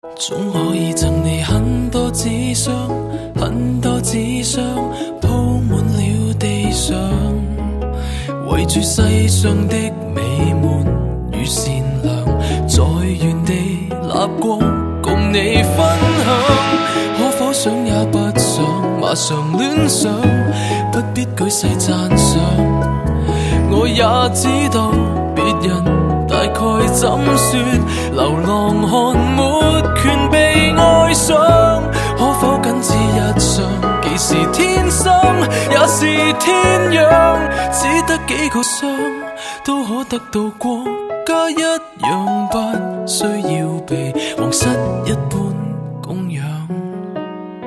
M4R铃声, MP3铃声, 华语歌曲 59 首发日期：2018-05-15 13:26 星期二